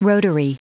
Prononciation du mot rotary en anglais (fichier audio)
Prononciation du mot : rotary